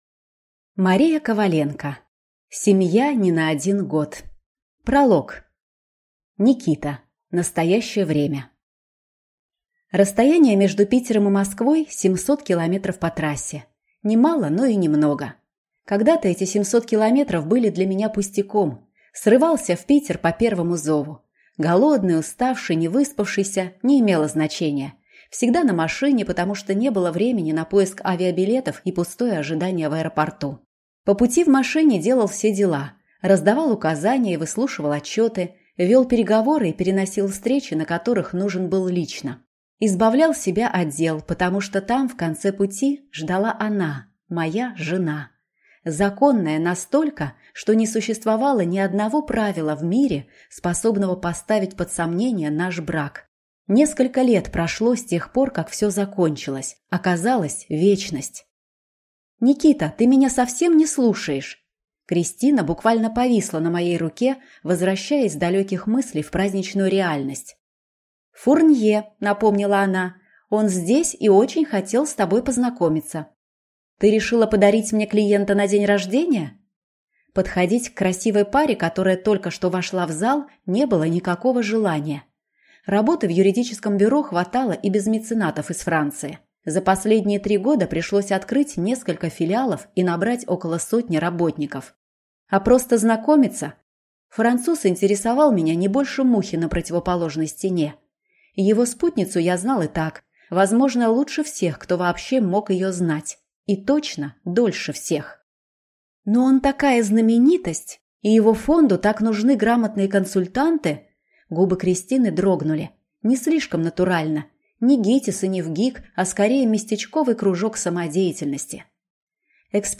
Аудиокнига Семья (не) на один год | Библиотека аудиокниг